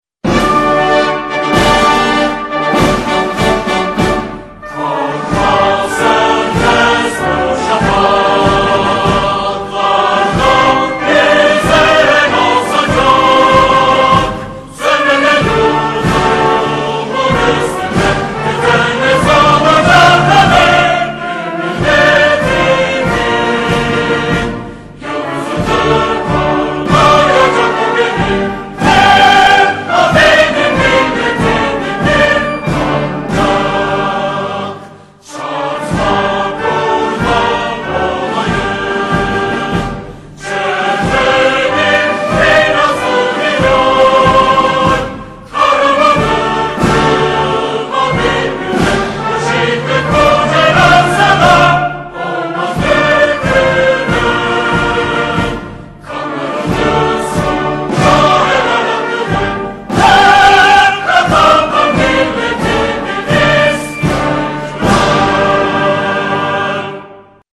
Turkish march